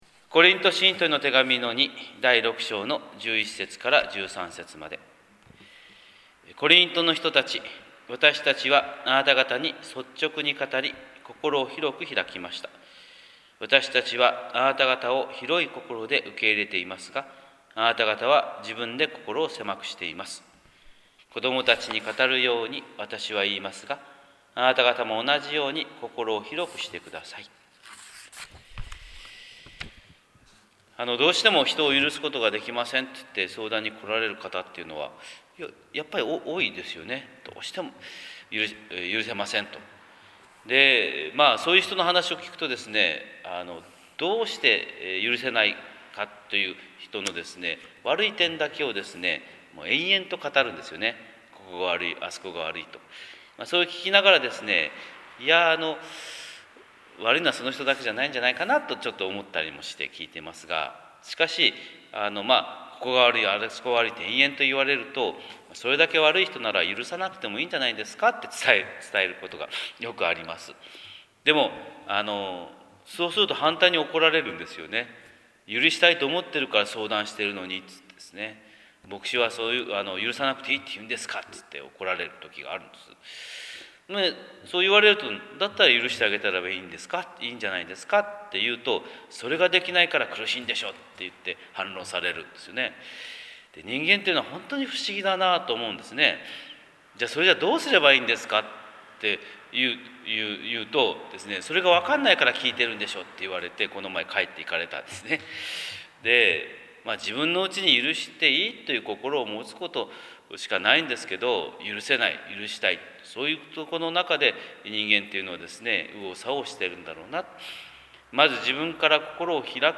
神様の色鉛筆（音声説教）: 朝礼拝131001